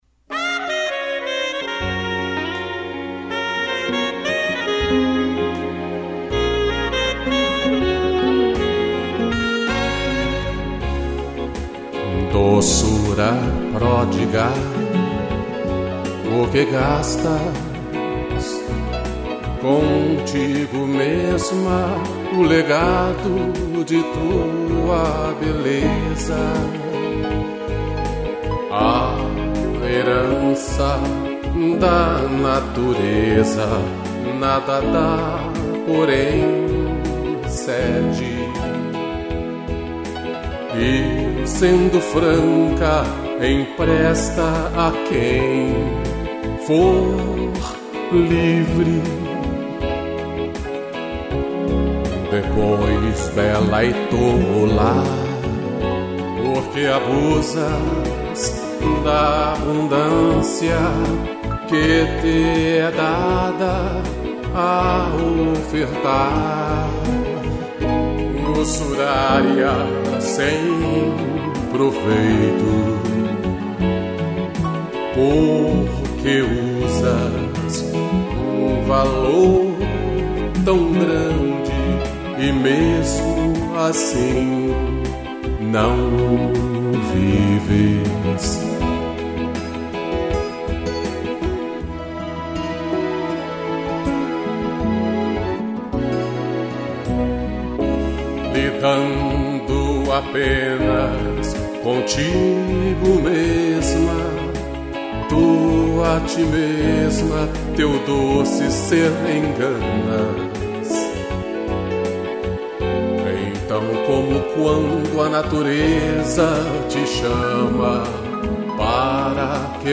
interpretação e violão
piano